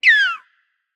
Minecraft Version Minecraft Version latest Latest Release | Latest Snapshot latest / assets / minecraft / sounds / mob / dolphin / idle_water7.ogg Compare With Compare With Latest Release | Latest Snapshot
idle_water7.ogg